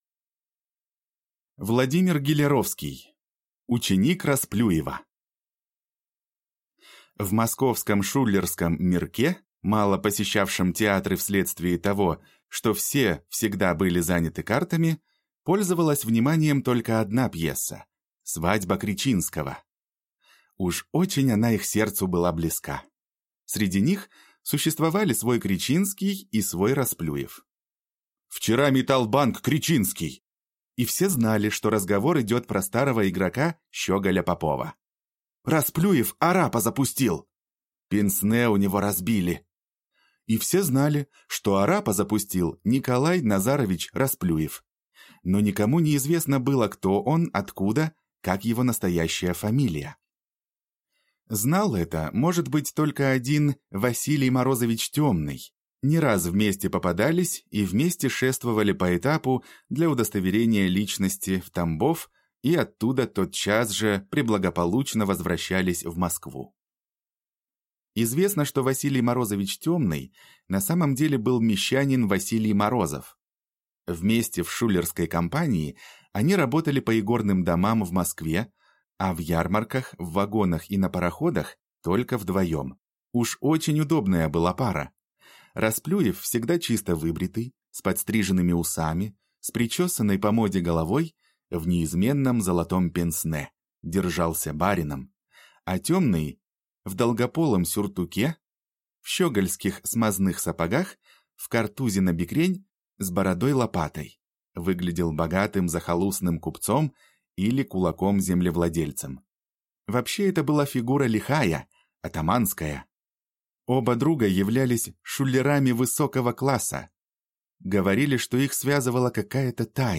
Аудиокнига Ученик Расплюева | Библиотека аудиокниг